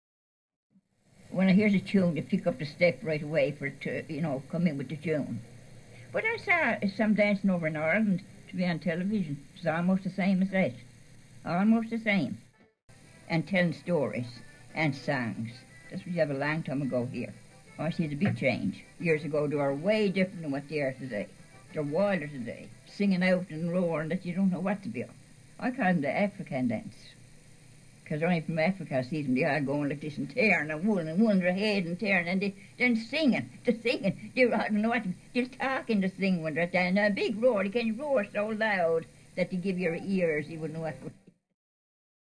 Newfoundland Irish English (conservative rural speaker of Irish background) — 2
Features of conservative rural Newfoundland Irish English (audible in the above sound files)
1) Alveolar stop realisation of TH and DH, e.g. there [de:r].
2) Very open realisation of back vowels, e.g. morning [mɒ:rnɪn], clock [klɒk].
3) Centralised onset for AI-diphthong before voiceless obstruents, e.g. night [nəɪt], but not to the same extent before voiced ones, e.g. five [fɐɪv].
4) Alveolar realisation of syllable-final /l/, e.g. all [ɒ:l].